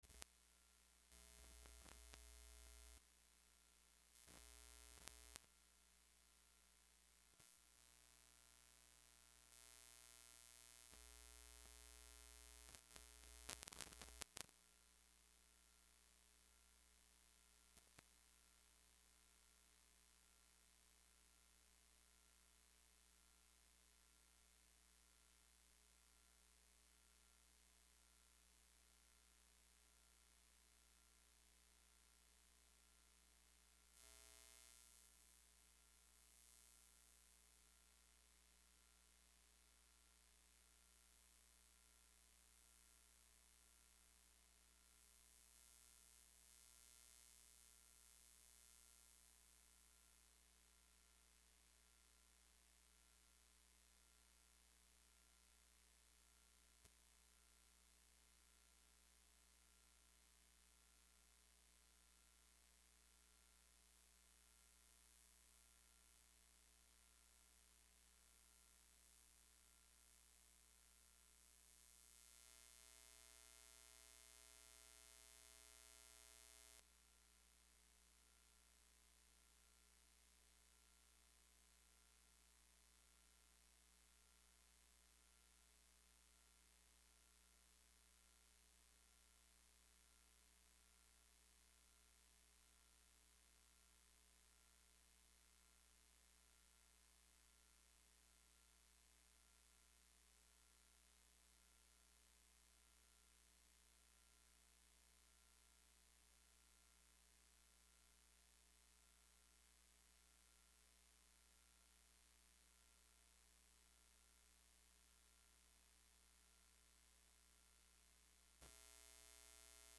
تاريخ النشر ٥ رجب ١٤٣٨ هـ المكان: المسجد الحرام الشيخ